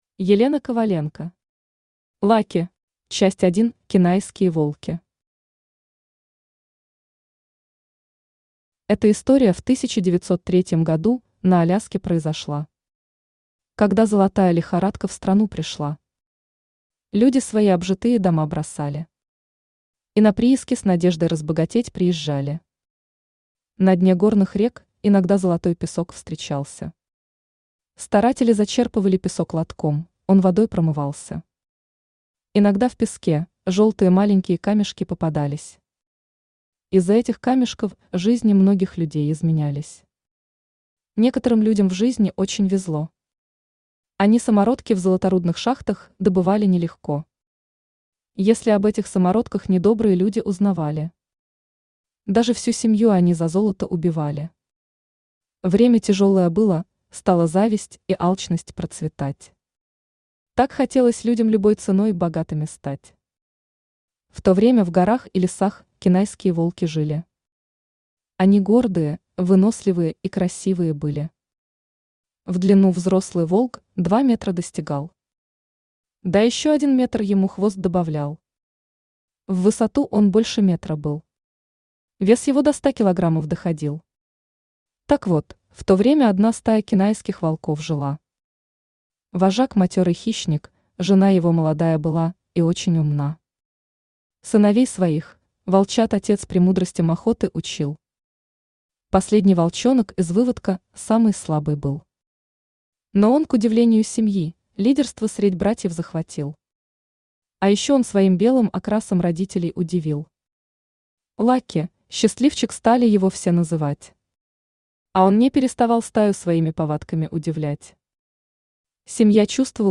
Аудиокнига Лаки | Библиотека аудиокниг
Aудиокнига Лаки Автор Елена Ивановна Коваленко Читает аудиокнигу Авточтец ЛитРес.